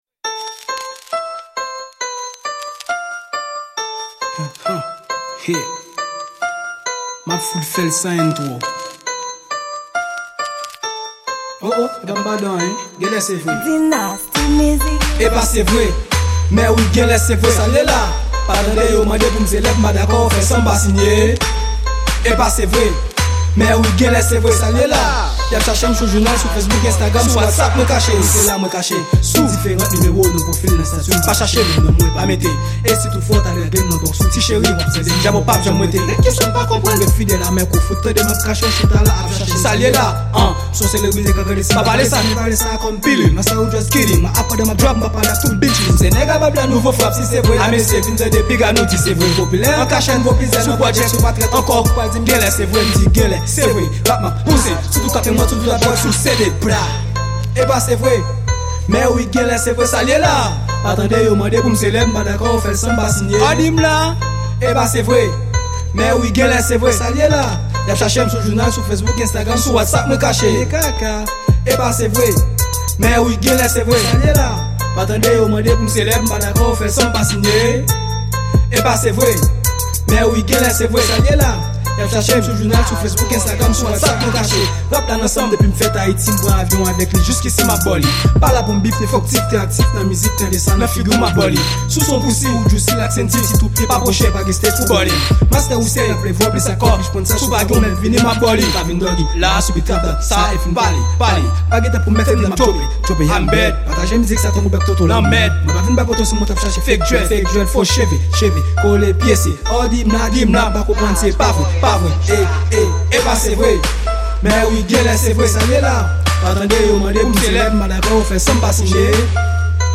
Genre Rap